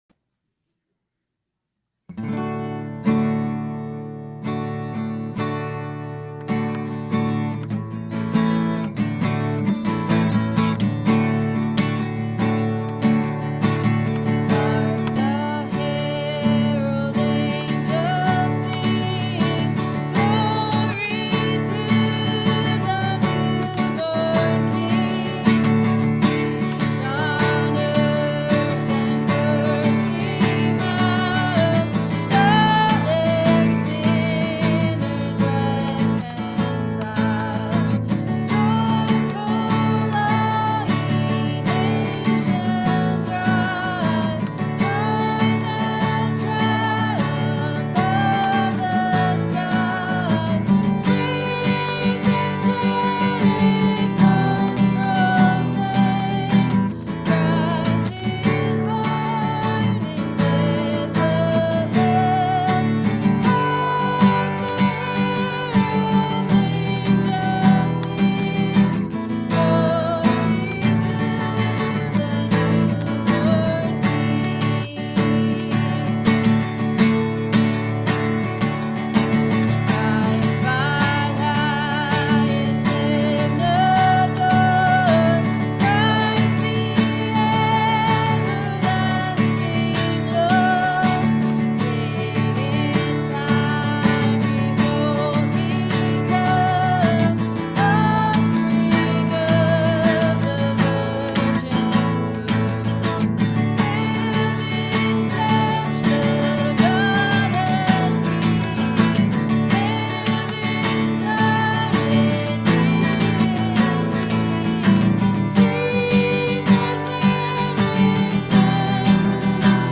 Christmas Eve Candlelight Service, 2024